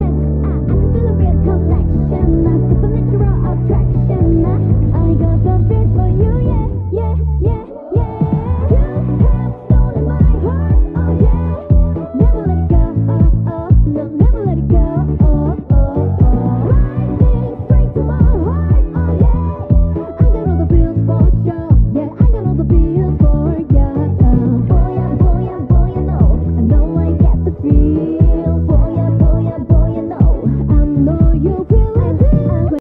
muffled ver